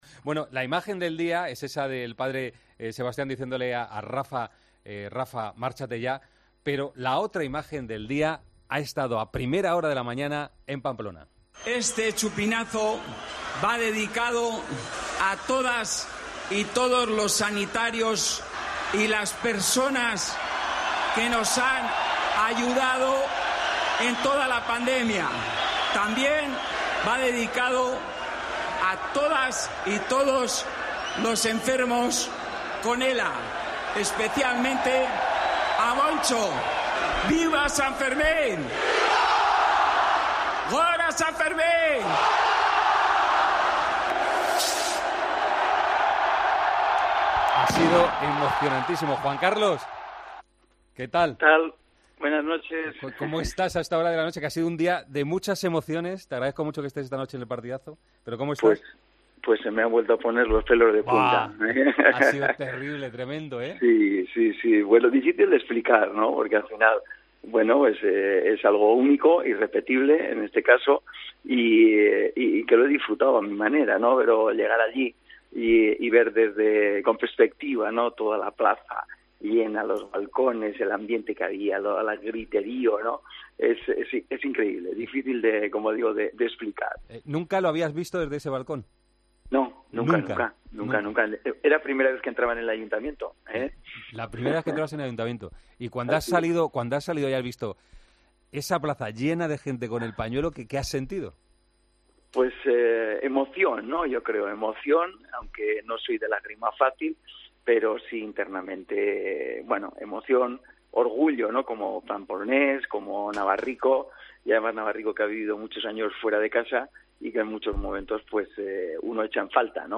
ENTREVISTA A JUAN CARLOS UNZUÉ, EN EL PARTIDAZO DE COPE